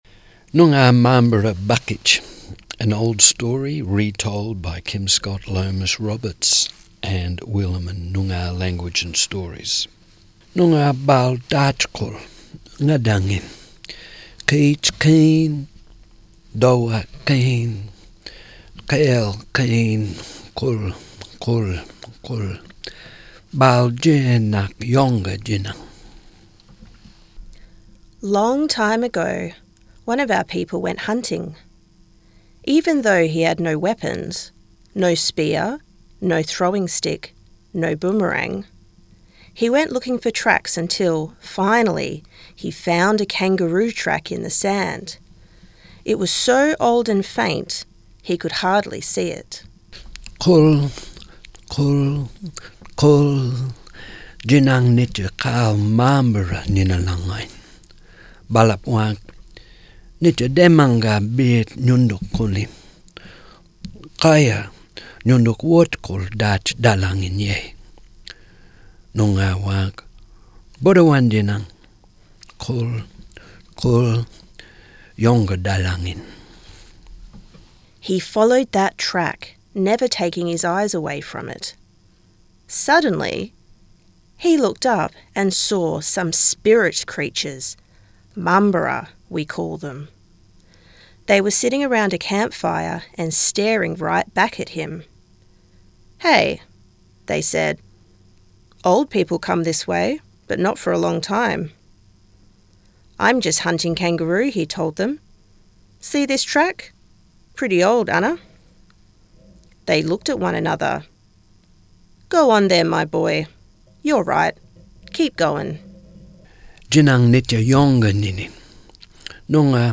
Noongar-Mambara-Bakitj-Reading-Edit.mp3